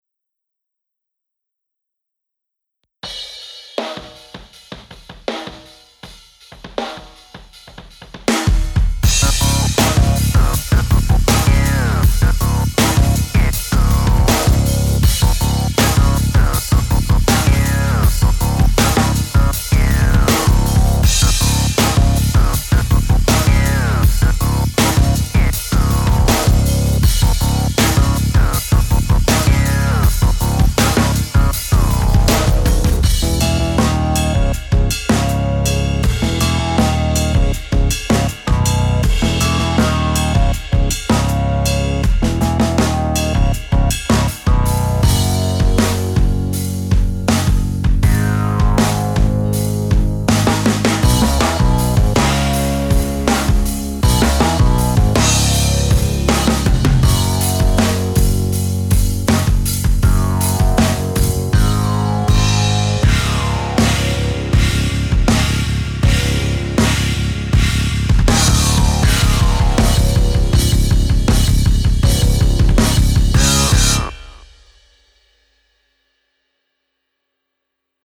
CreativePowerChords-pt2-BackingTrack.mp3